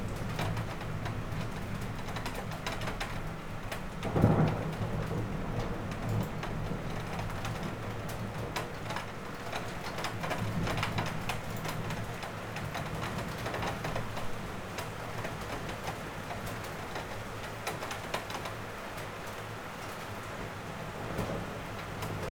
Index of /audio/samples/SFX/IRL Recorded/Rain - Thunder/
Rain 5.wav